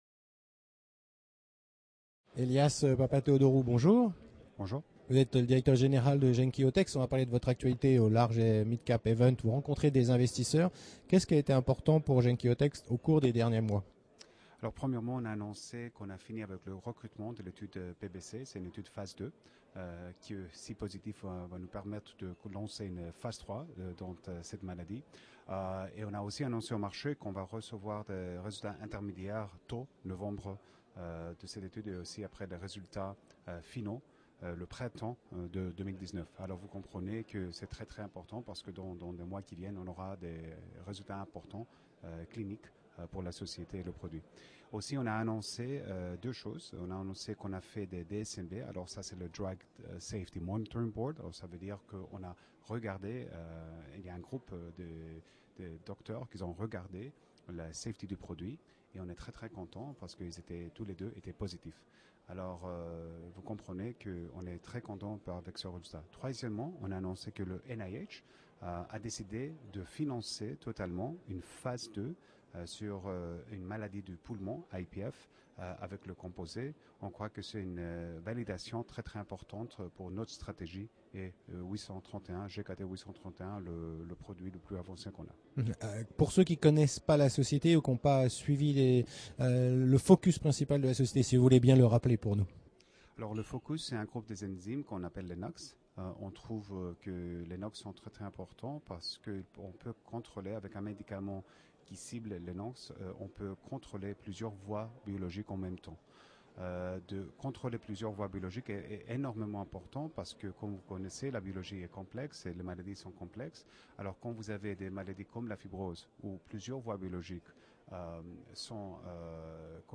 La Web Tv rencontre les dirigeants au Paris – European Large et Midcap Event